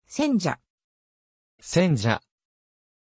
Prononciation de Senja
pronunciation-senja-ja.mp3